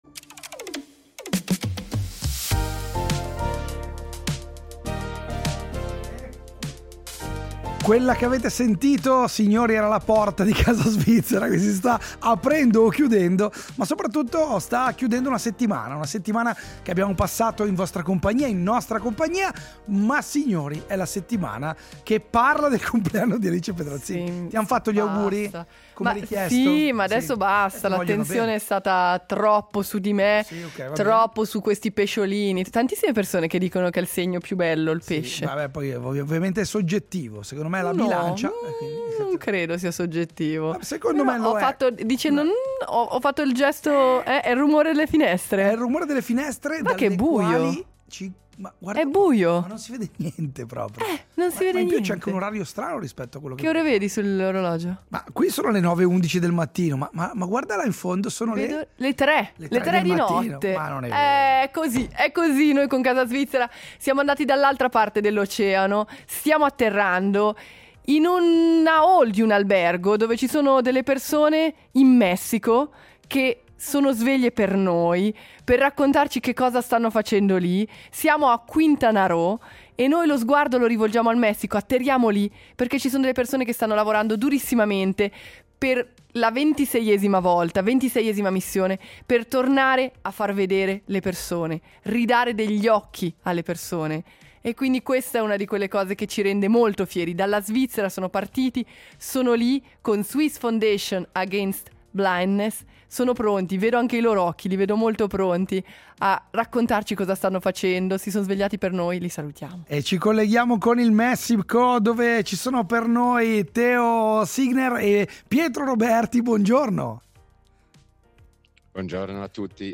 Poi siamo tornati in studio… ma il viaggio è continuato.